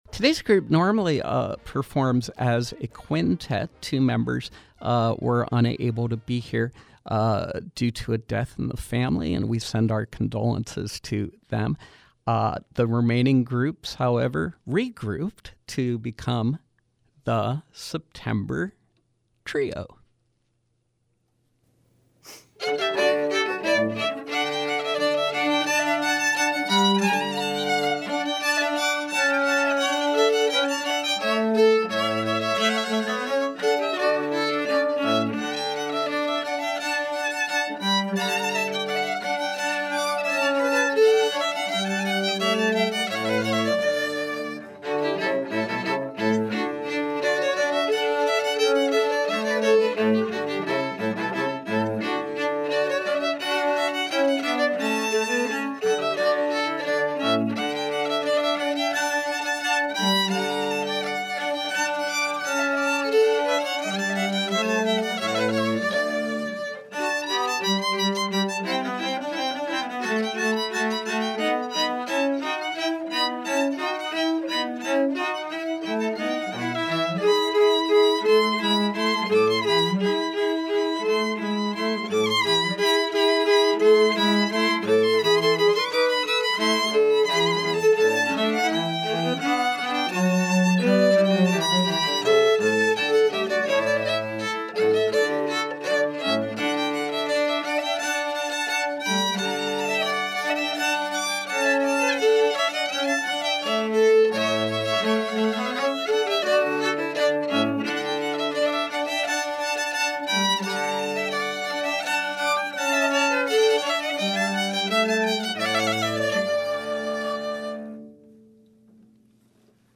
cello
violin
viola